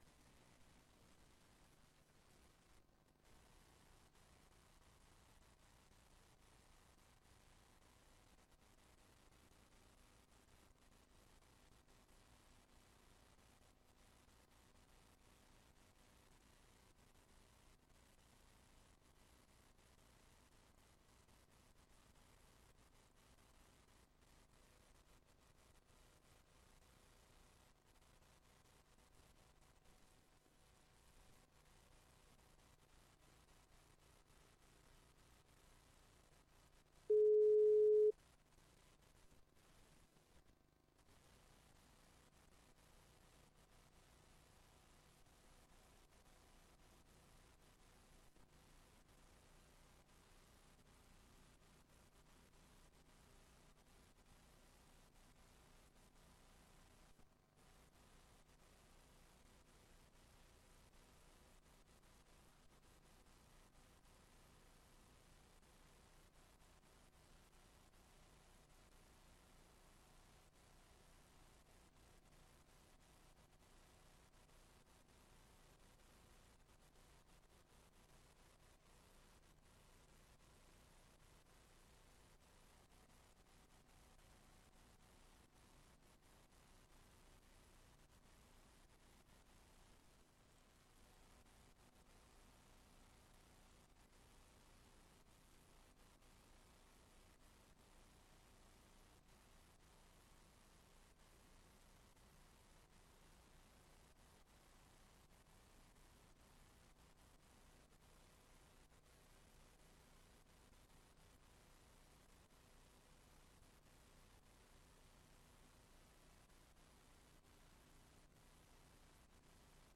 Raadsbijeenkomst 08 april 2025 19:15:00, Gemeente Tynaarlo